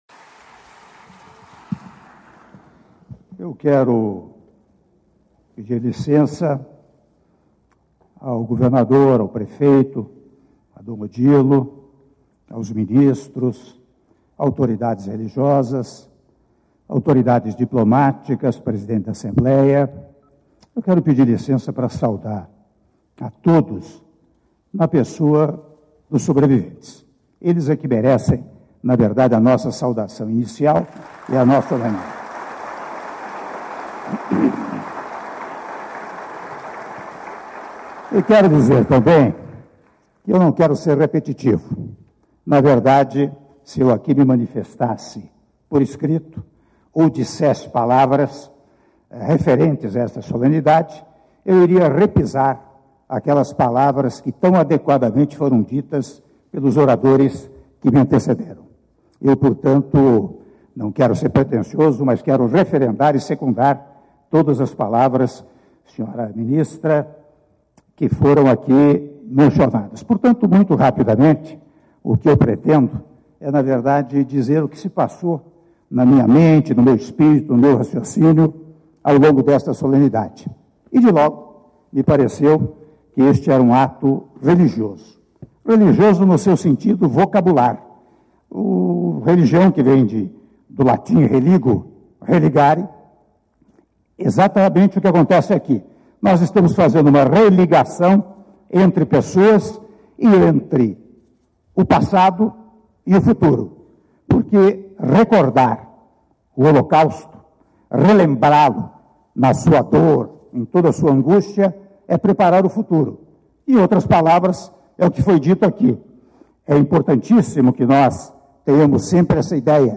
Áudio do discurso do presidente da República, Michel Temer, durante cerimônia do Dia Internacional em Memória das Vítimas do Holocausto - São Paulo/SP - (04min34s) — Biblioteca